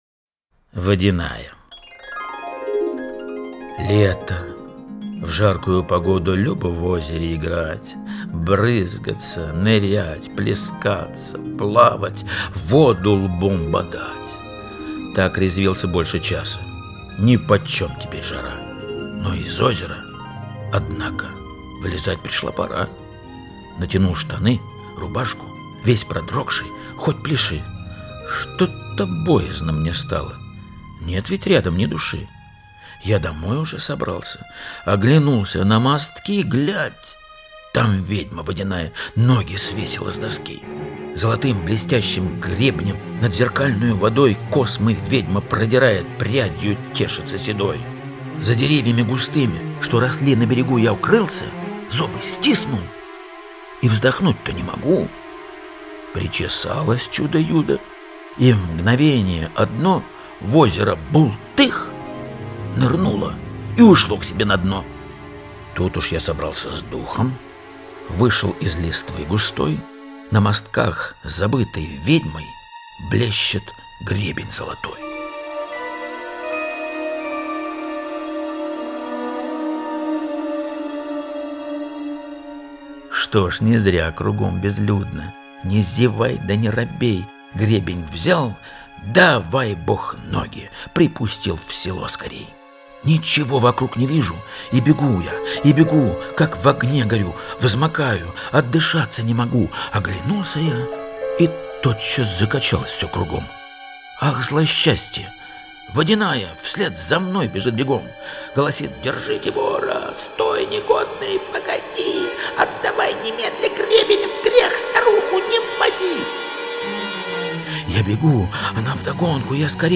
Габдулла Тукай – Водяная (читает С. Шакуров)